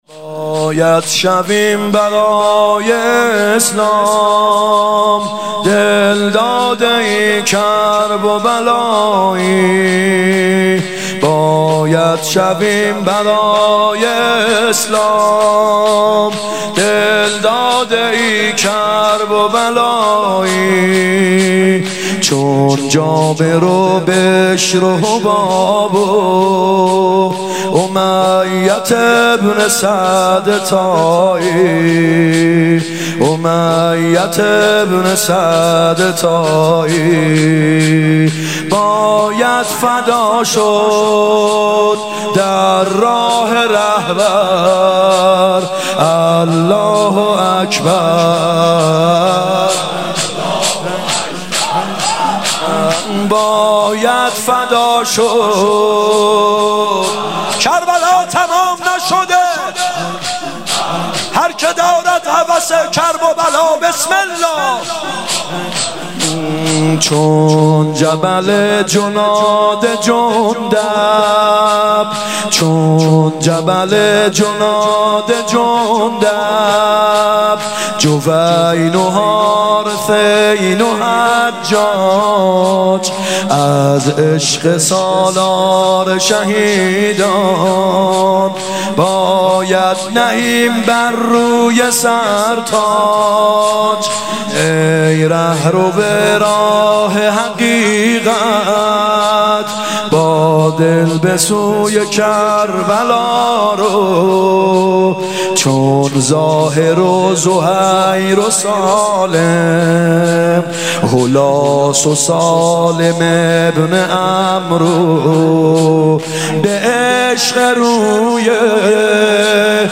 هیات یامهدی عج(محرم 96)